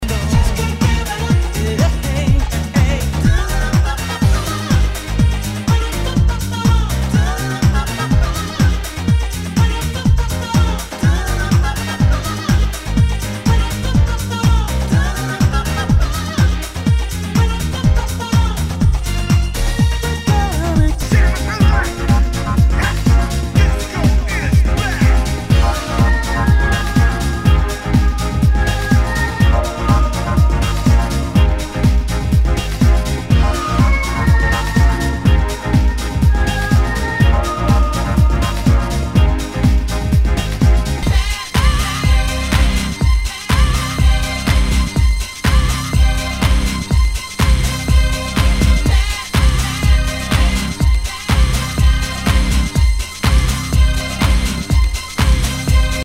HOUSE/TECHNO/ELECTRO
ナイス！ディープ・ハウス・クラシック！
全体にチリノイズが入ります